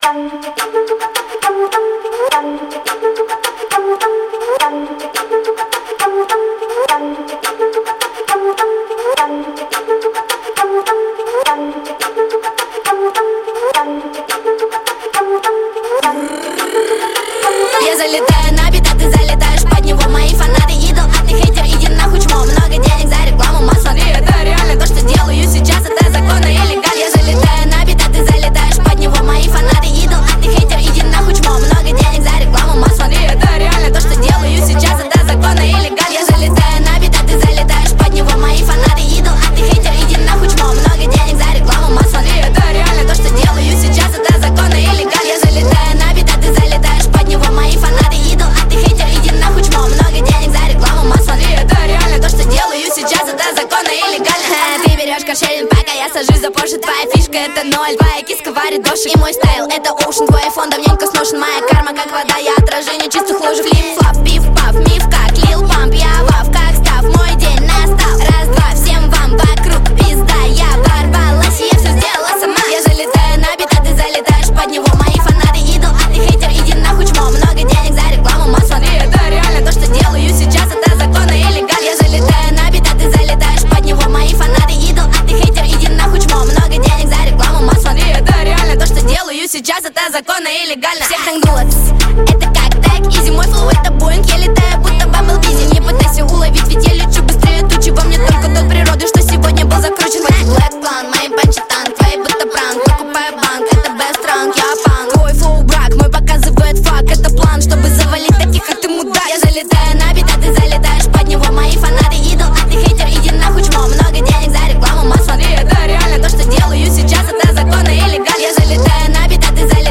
Хип-хоп
Жанр: Жанры / Хип-хоп